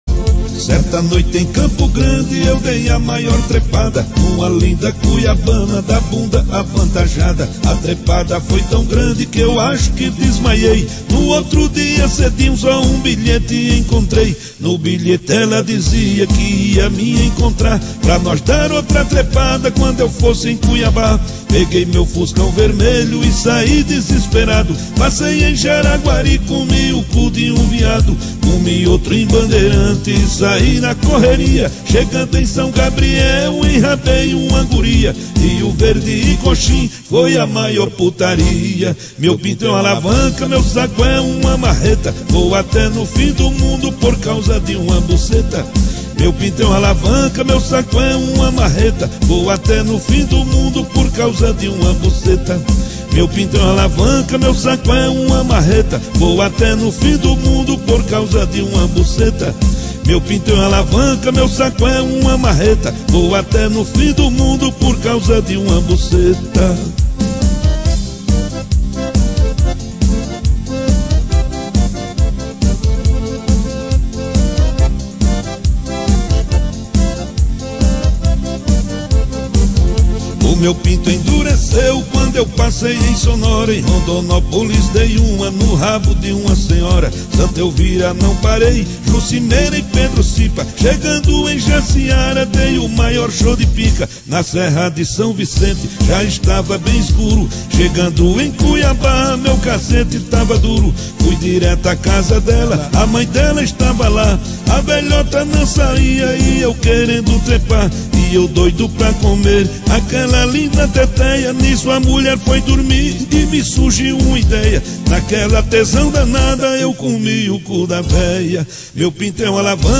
2024-02-21 20:42:10 Gênero: Forró Views